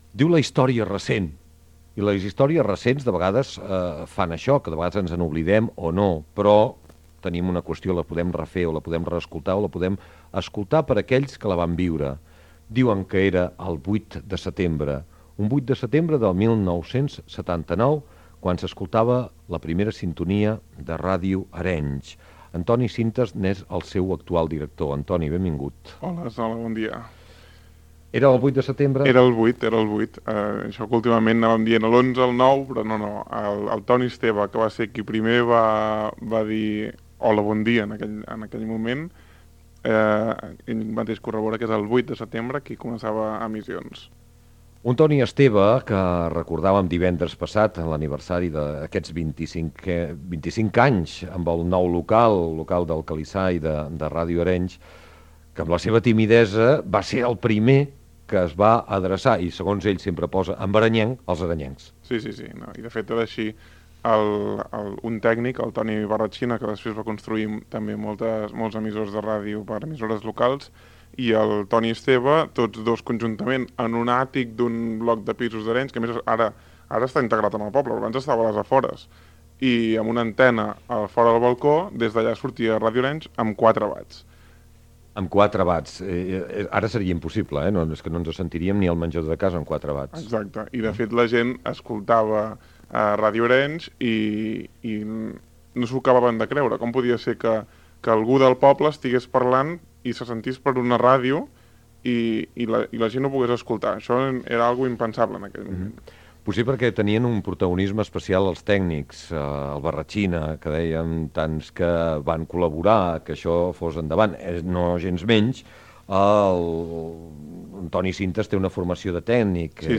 Divulgació
Fragment extret de l'arxiu sonor de COM Ràdio.